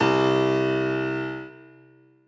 b_basspiano_v127l1o3c.ogg